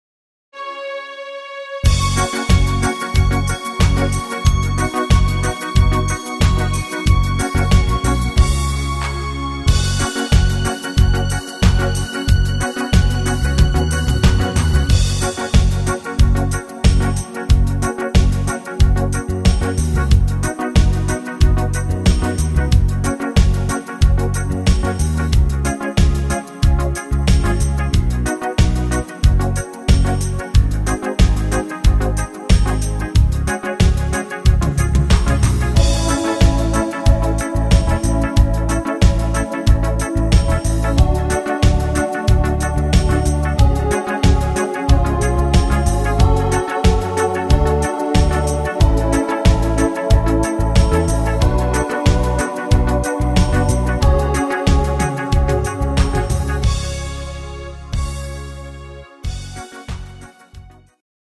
Rhythmus  Light Reggae
Art  Schlager 90er, Deutsch